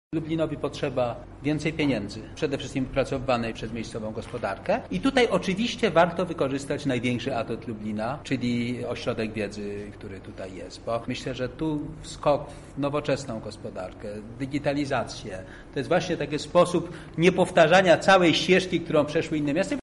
Więcej na ten temat mówi współautor raportu prof. Witold Orłowski: